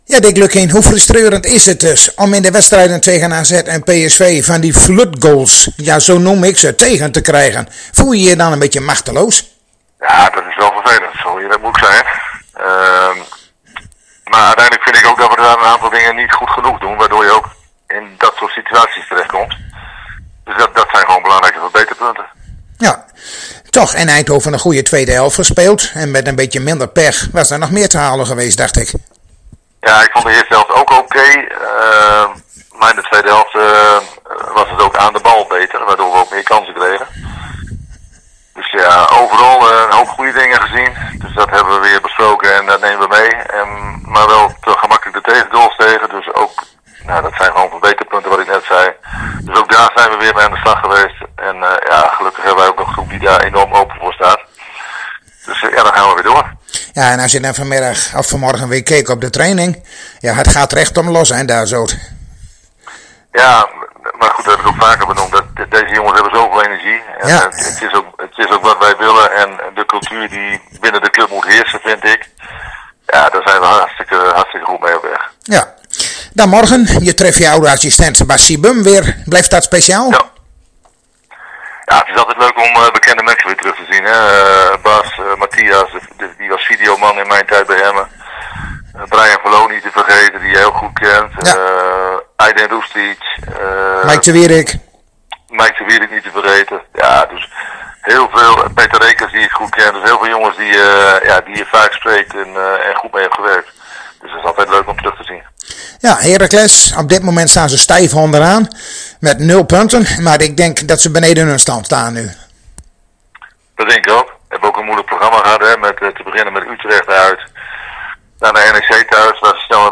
Zojuist spraken we weer met trainer Dick Lukkien over de wedstrijd van morgen tegen Heracles Almelo en een aantal foto's van de training van hedenmorgen plaatsen we hier.